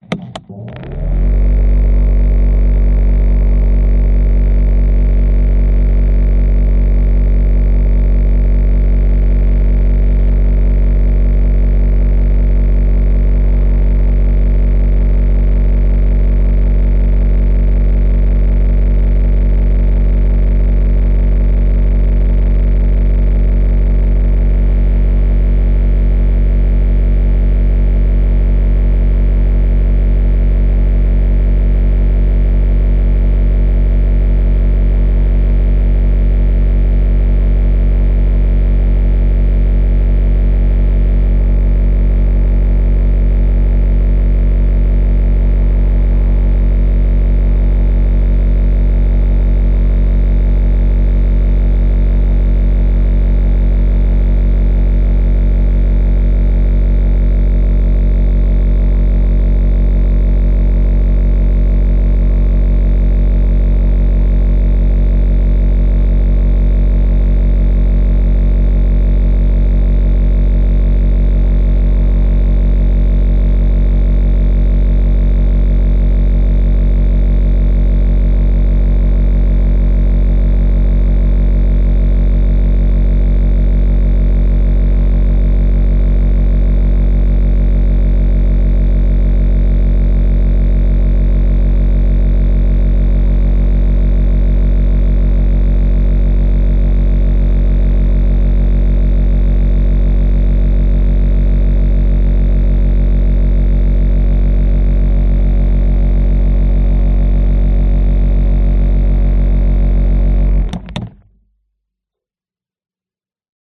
Звуки спа, атмосфера
микрофон контакта с вибрационным режимом массажа воды